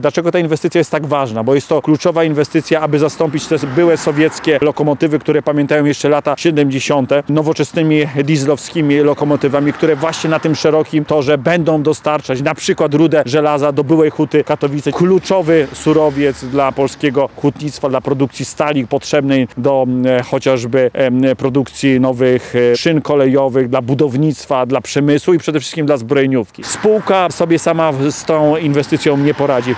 Poseł Prawa i Sprawiedliwości Janusz Kowalski zapowiedział w sobotę (14.02) podczas konferencji prasowej w Zamościu interwencję poselską w Ministerstwie Infrastruktury.